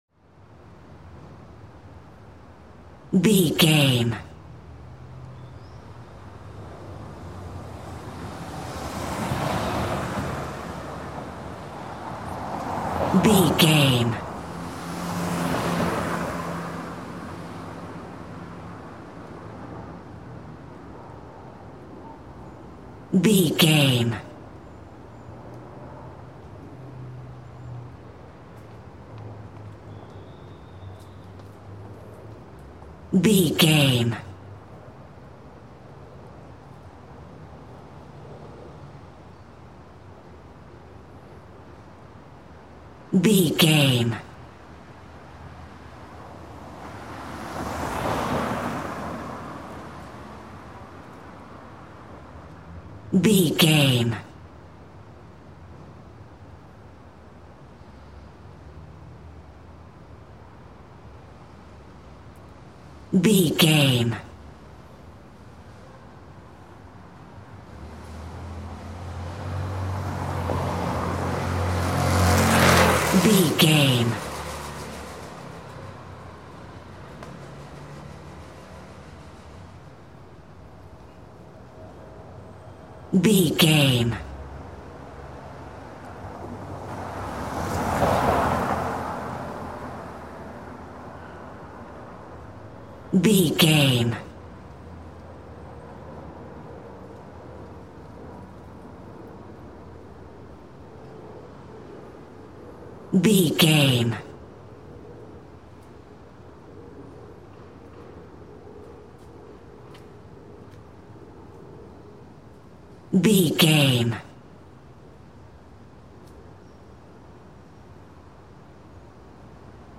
City rumble calm street
Sound Effects
urban
calm
ambience